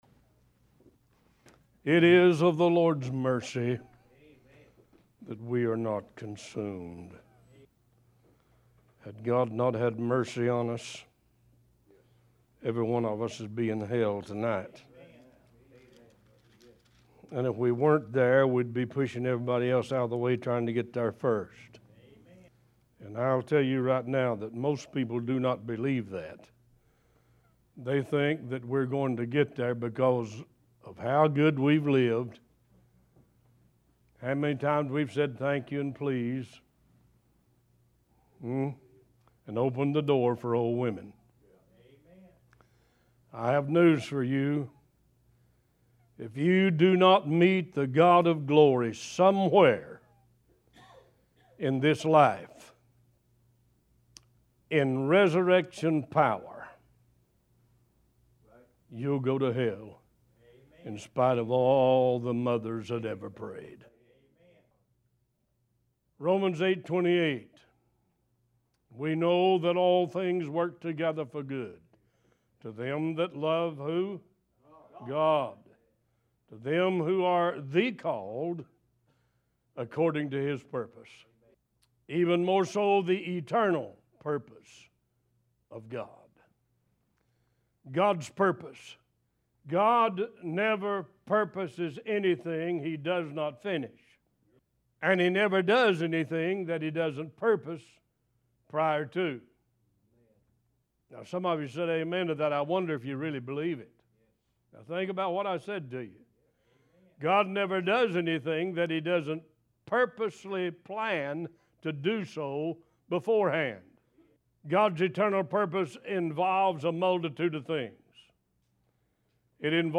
One Voice Talk Show